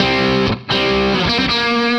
Index of /musicradar/80s-heat-samples/120bpm
AM_HeroGuitar_120-C02.wav